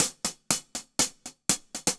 Index of /musicradar/ultimate-hihat-samples/120bpm
UHH_AcoustiHatB_120-05.wav